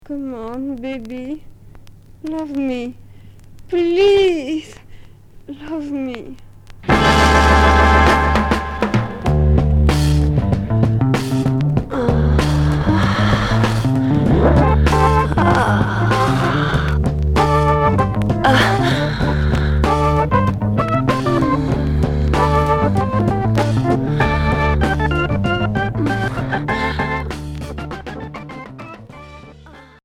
Erotico groove